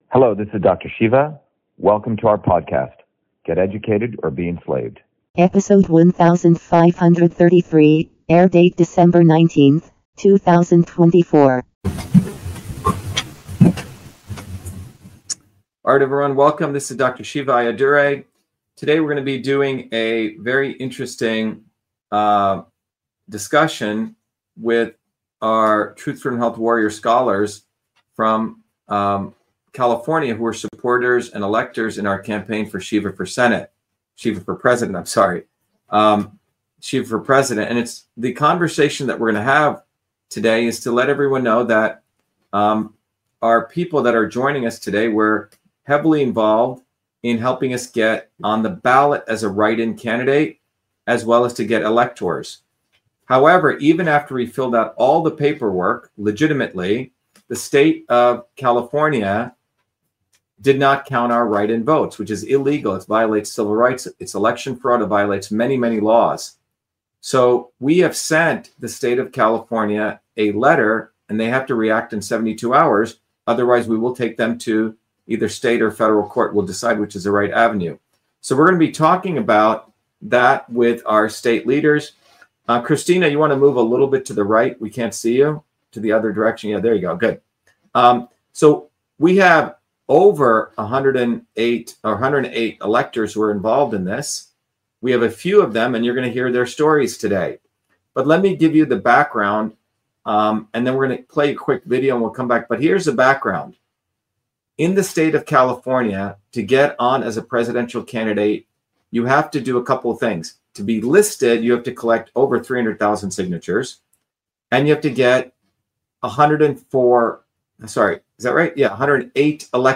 In this interview, Dr.SHIVA Ayyadurai, MIT PhD, Inventor of Email, Scientist, Engineer and Candidate for President, Talks about California Did NOT Count Shiva 4 President Votes WHY?